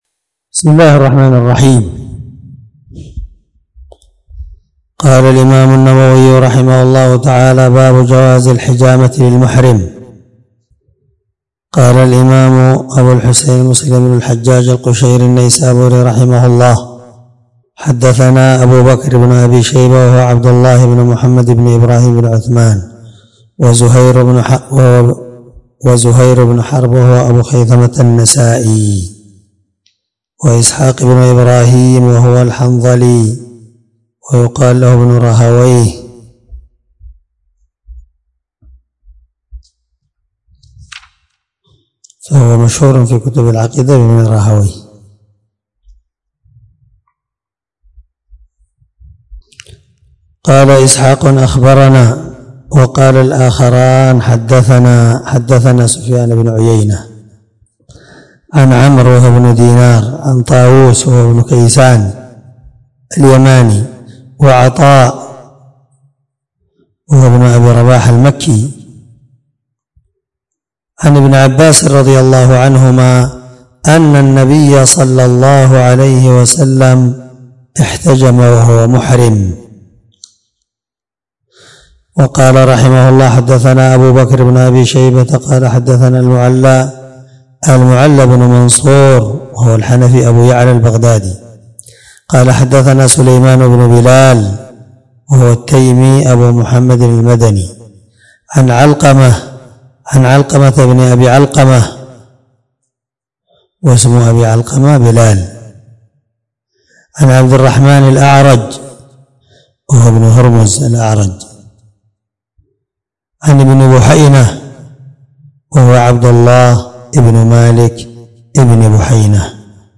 726الدرس 11من شرح كتاب الحج حديث رقم(1202-1204) من صحيح مسلم
دار الحديث- المَحاوِلة- الصبيحة.